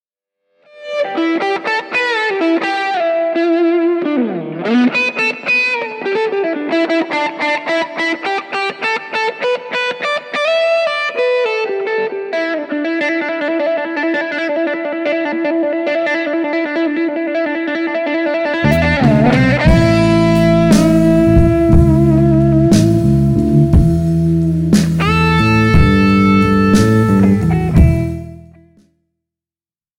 Gravado ao vivo